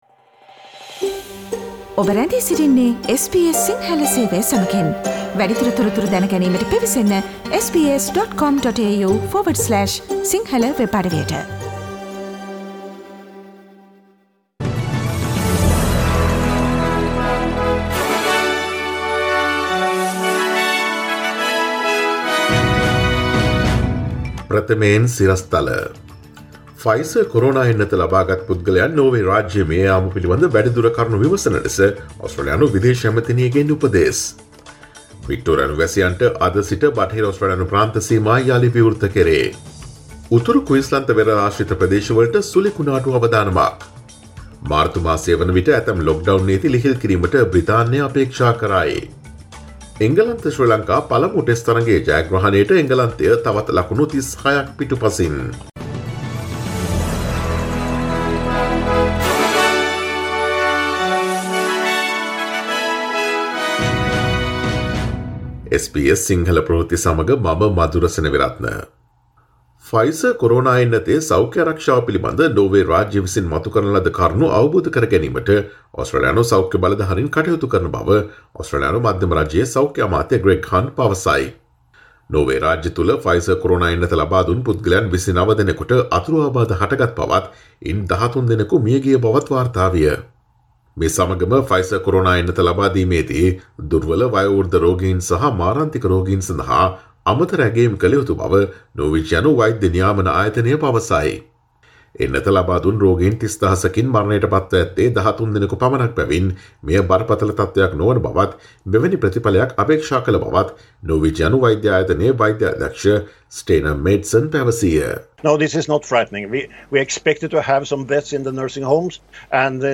Today’s news bulletin of SBS Sinhala radio – Monday 18 January 2021